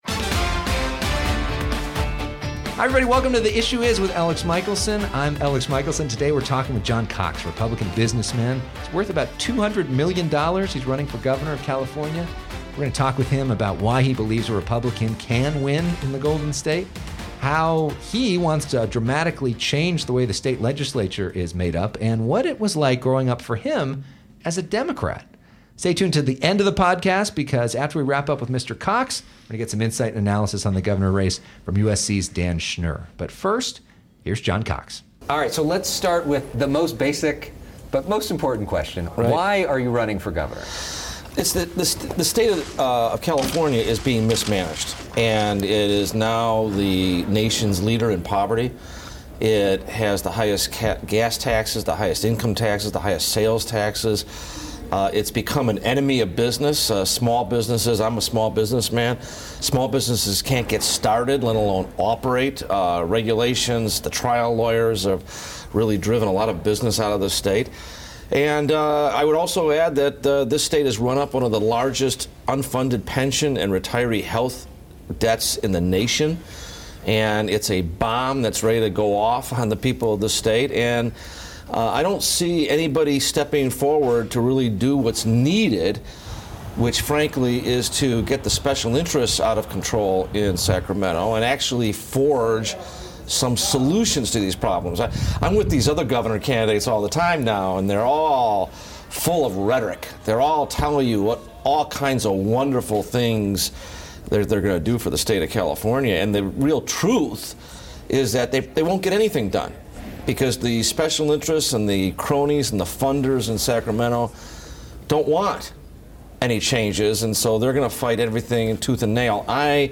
is California's only statewide political show, broadcast from FOX 11 Studios in Los Angeles.